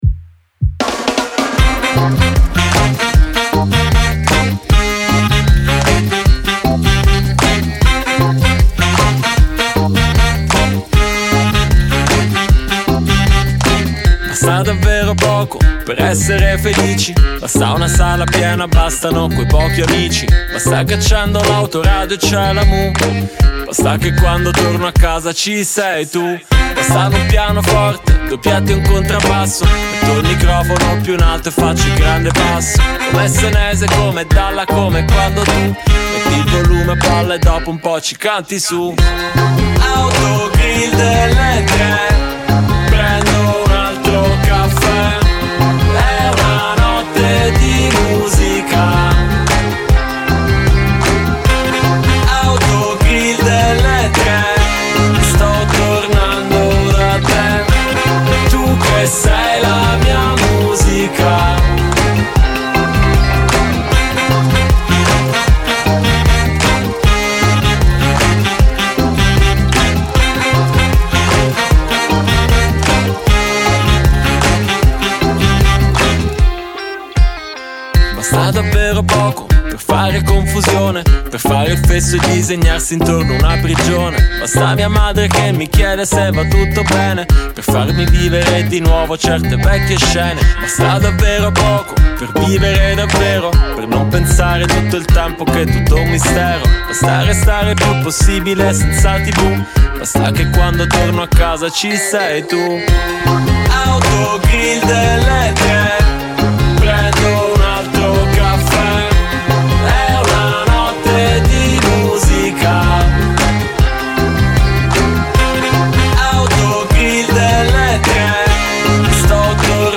Prova-rap-2.mp3